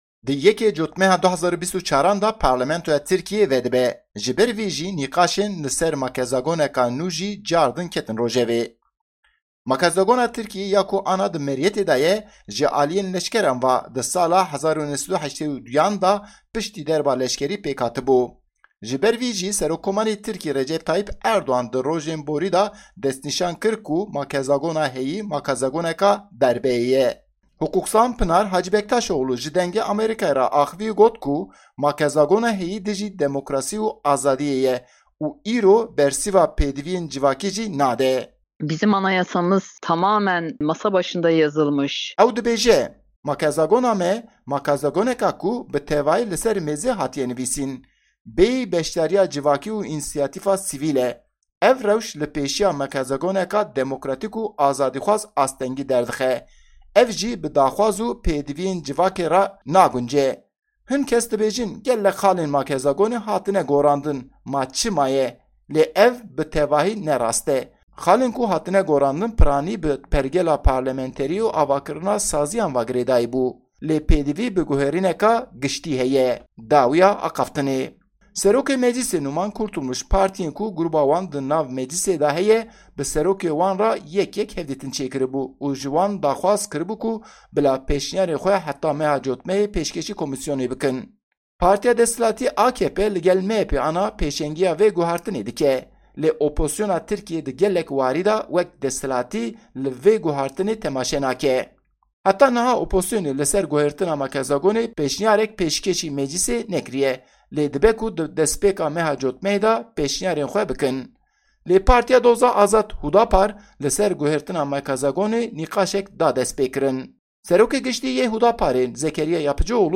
Raporta Deng